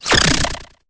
Cri_0854_EB.ogg